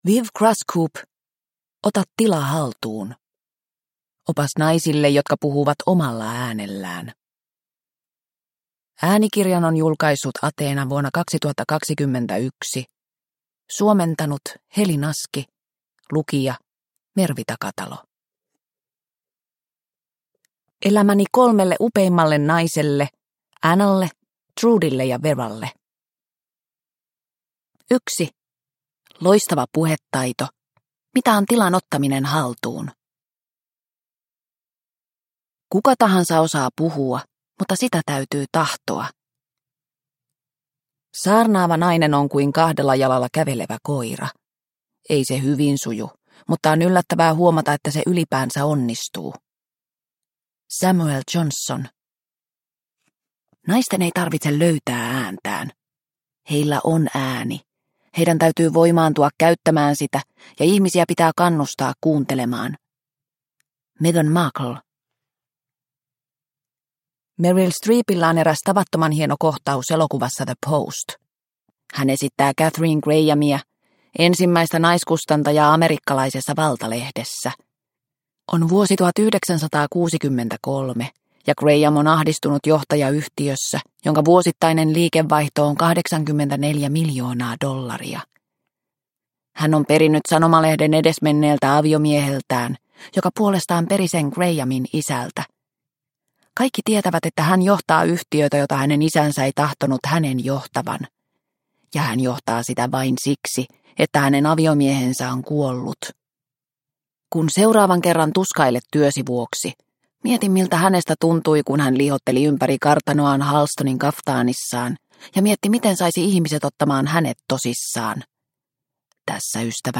Ota tila haltuun – Ljudbok – Laddas ner